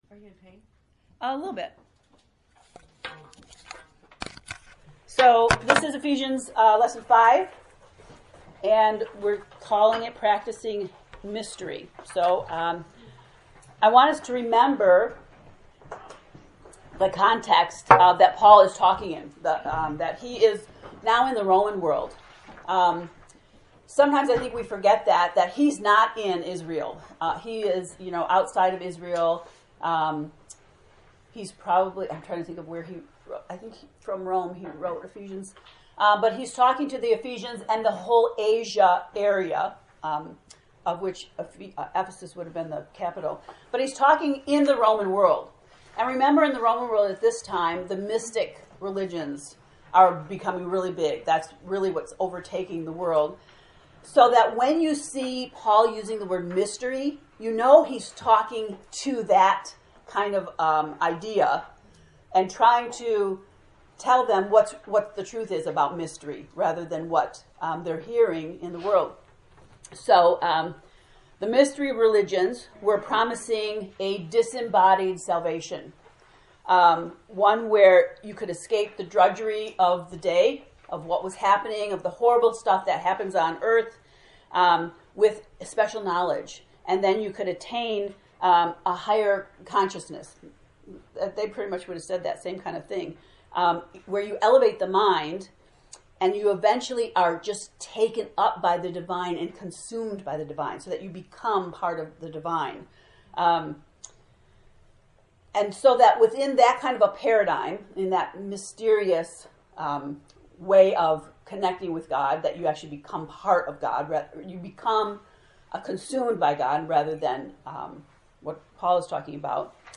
To listen to the lesson 5 lecture, “Practicing Mystery,” click below: